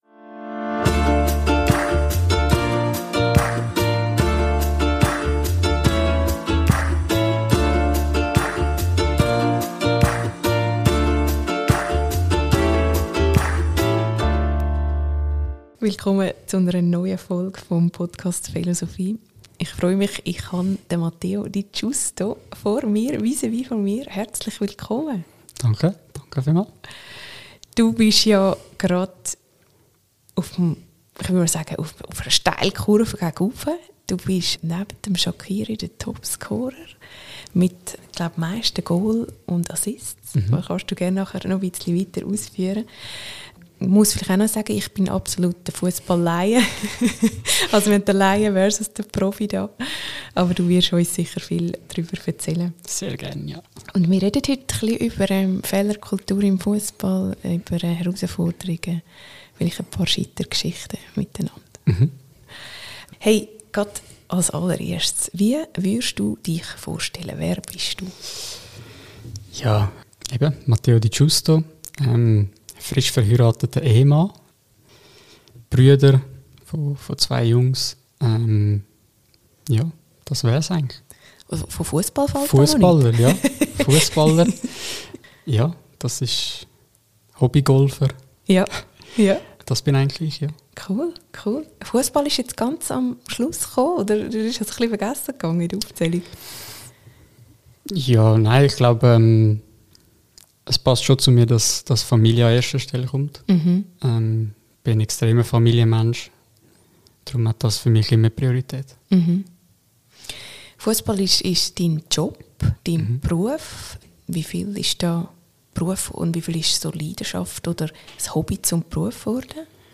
Beschreibung vor 3 Monaten Matteo Di Giusto erzählt in seiner ruhigen und bedachten Art, wie er mit Herausforderungen umgeht, dass im Fussball ständig Fehler passieren – genauso wie schnelle Erfolge und man beides schnell verarbeiten muss, um den Blick nach vorne richten zu können. Er erzählt ganz persönlich, wie er sich mental fit hält, was ihn erdet und wie er gelernt hat, Rückschläge als Teil des Weges zu akzeptieren.